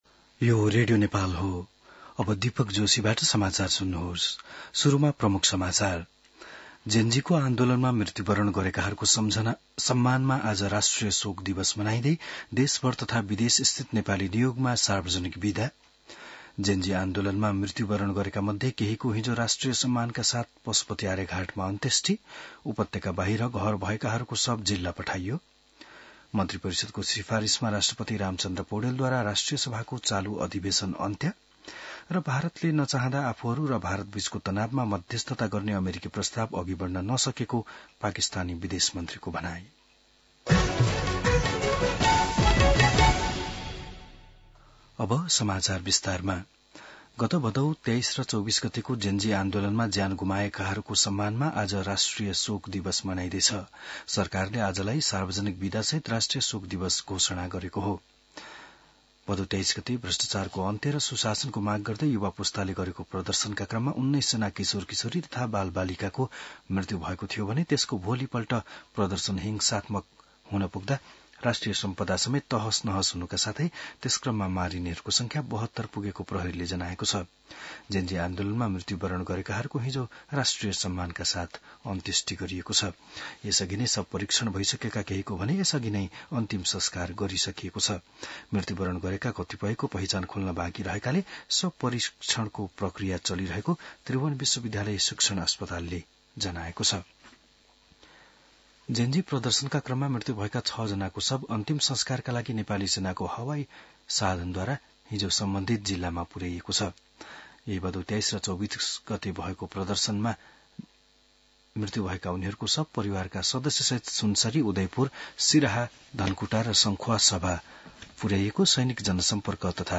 बिहान ९ बजेको नेपाली समाचार : १ असोज , २०८२